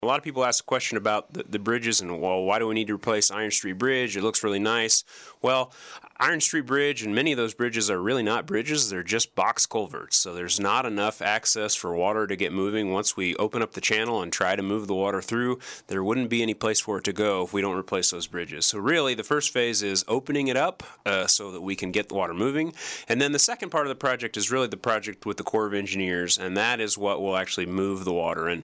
Interim City Manager Jacob Wood joined in on the KSAL Morning News Extra Tuesday with a look at a range of issues, including the project to bring the river back to life.